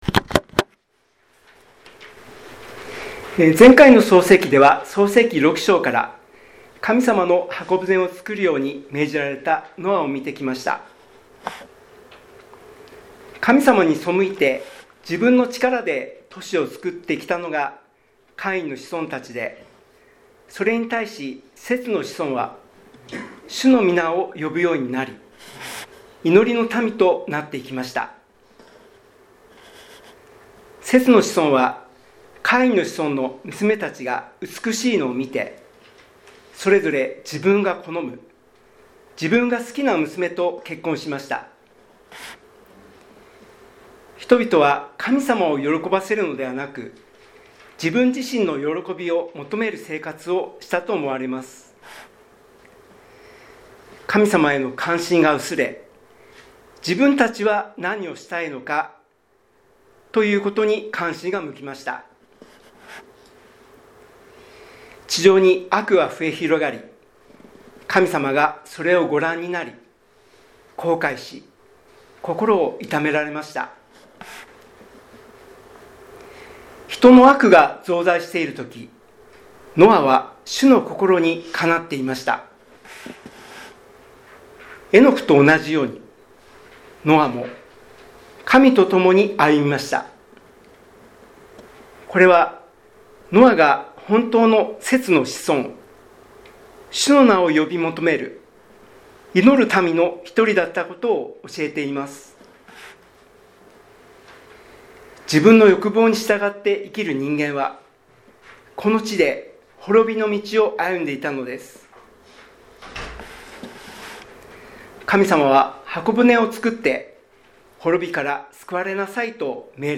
2026年2月15日聖日